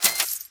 Item Purchase (2).wav